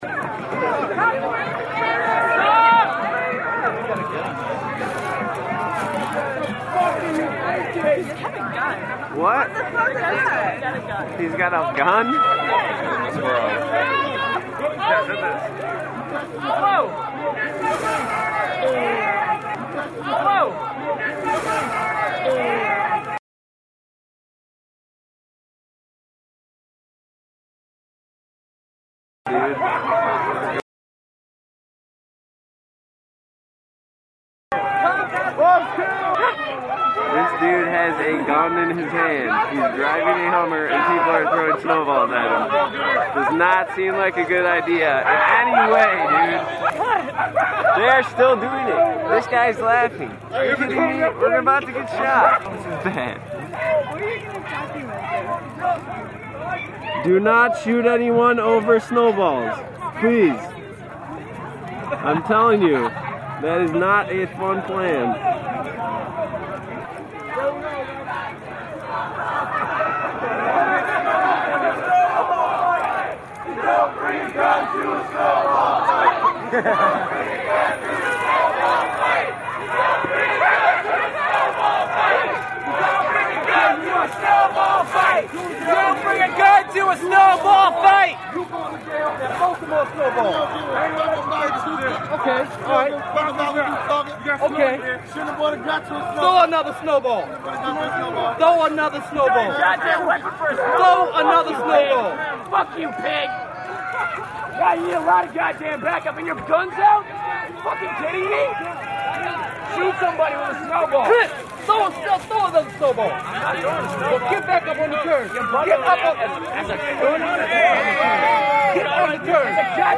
Approximately 5 minutes; harsh language throughout.